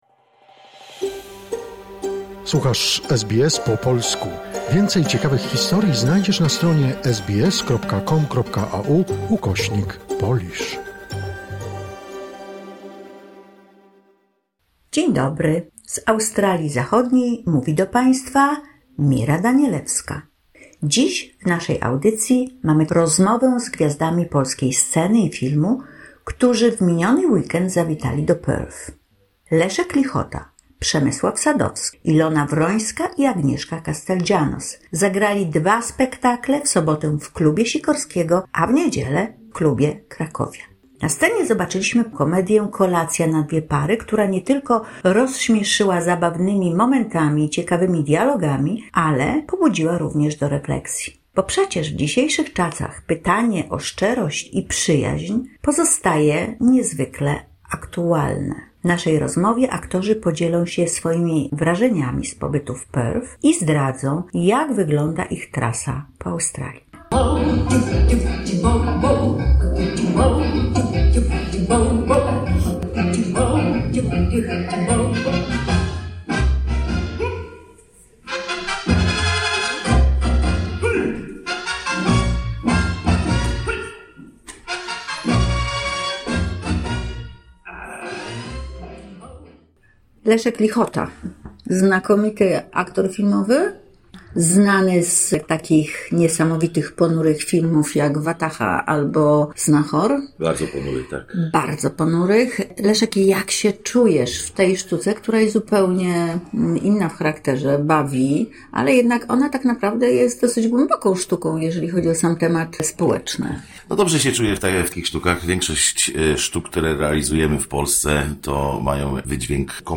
Aktorzy opowiadają o spektaklu „Kolacja na dwie pary” i swoich wrażeniach z Perth.